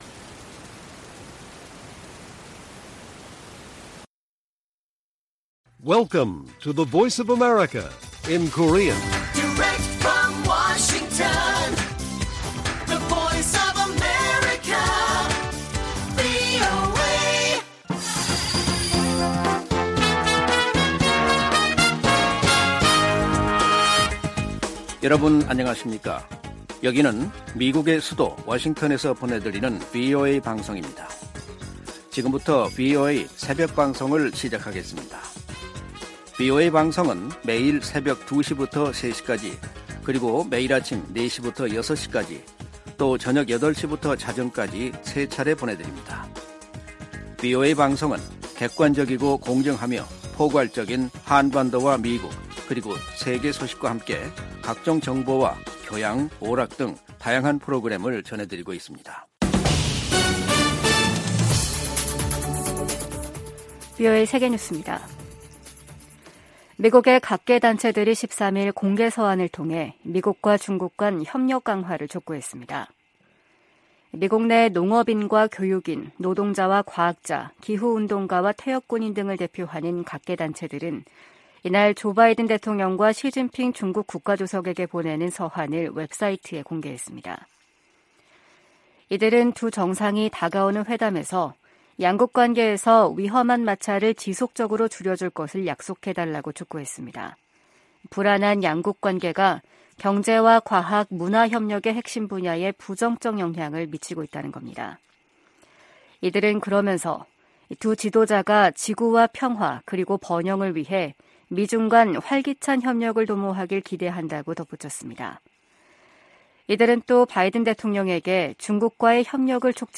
VOA 한국어 '출발 뉴스 쇼', 2023년 11월 15일 방송입니다. 한국을 방문한 로이드 오스틴 미 국방장관은 한반도 평화와 안정에 대한 유엔군사령부의 약속은 여전히 중요하다고 강조했습니다. 미 국무부는 이번 주 열리는 아시아태평양 경제협력체(APEC) 회의를 통해 내년도 역내 협력을 위한 전략적 비전이 수립될 것이라고 밝혔습니다. 15일 미중정상회담에서 양자 현안뿐 아니라 다양한 국제 문제들이 논의될 것이라고 백악관이 밝혔습니다.